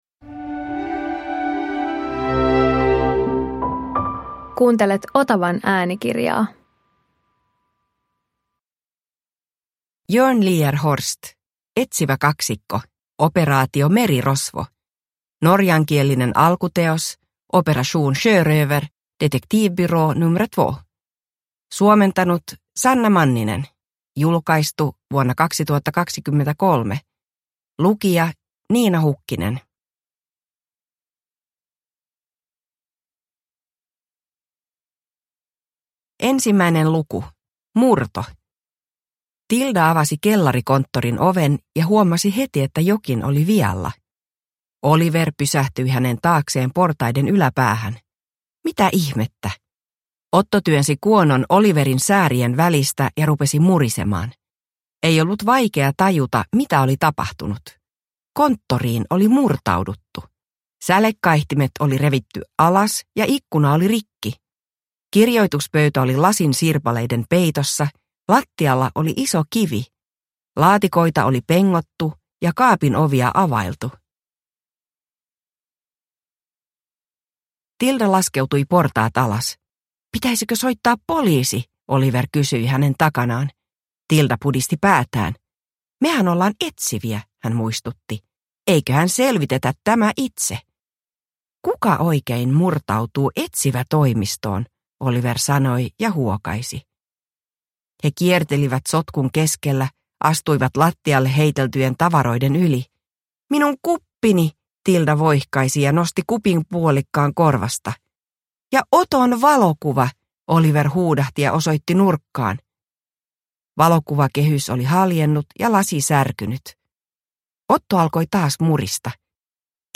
Operaatio Merirosvo – Ljudbok – Laddas ner